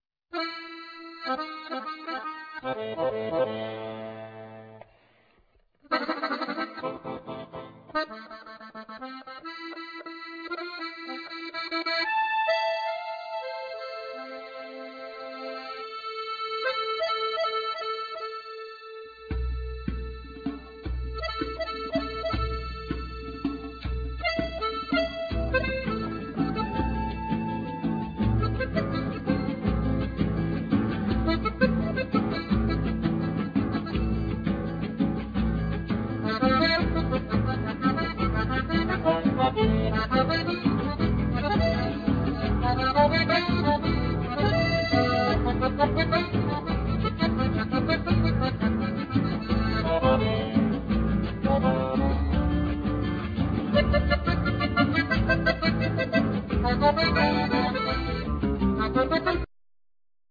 Accordion,Vocal,Percussions
Harp,Percussions
Bandoneon
Guitar
Double bass